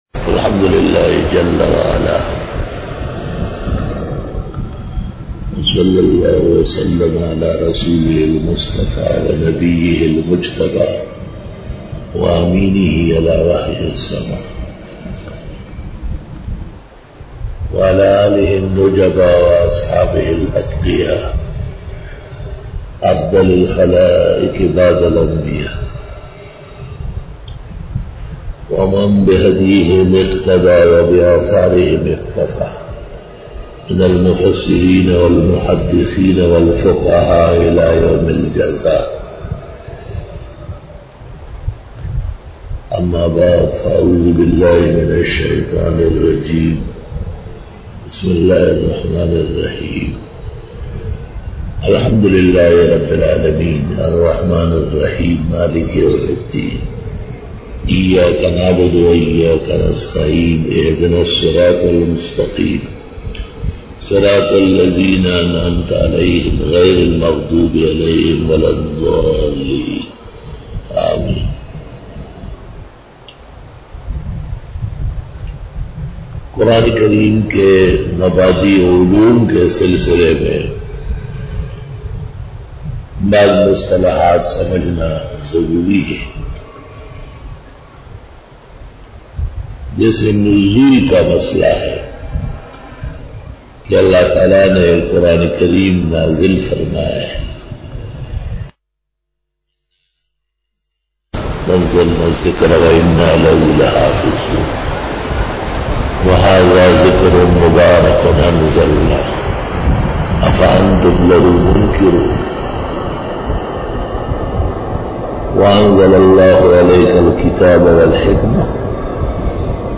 دورہ تفسیر